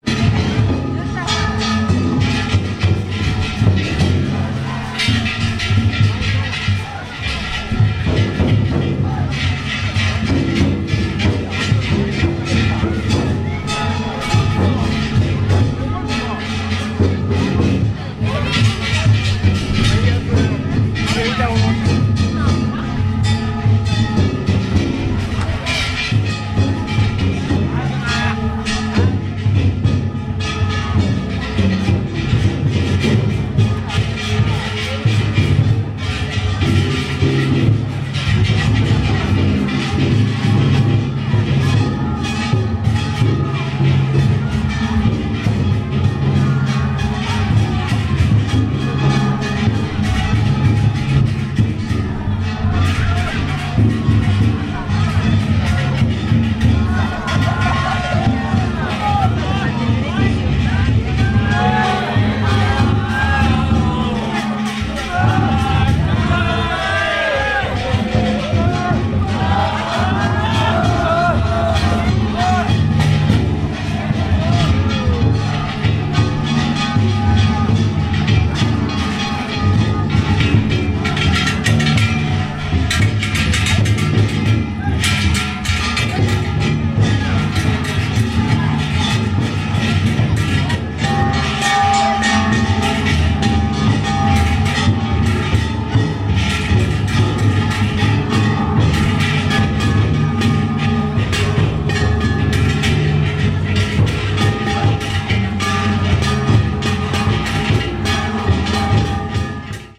旧市街各所が、けたたましい鉦や太鼓の音に包まれる祭です。
試楽・夜8時の八間通(110秒・2.1MB)